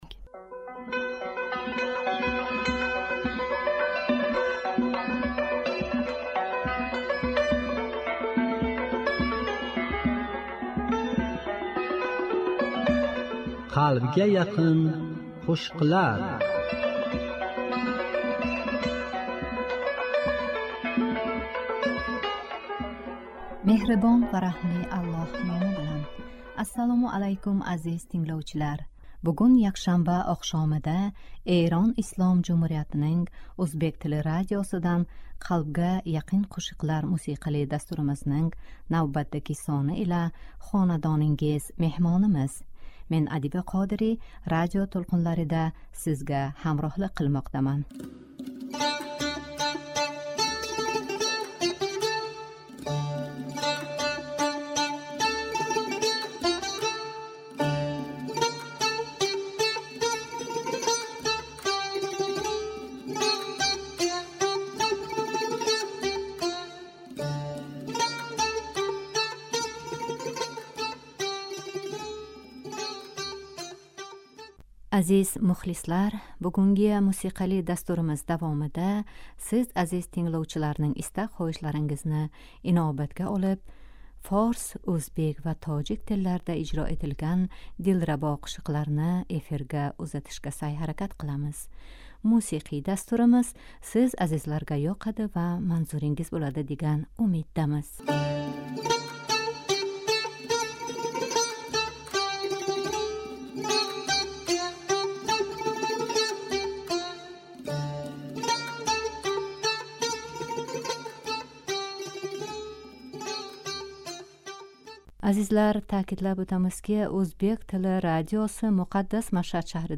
кайфиятни кутарувчи ва чиройли қўшиқ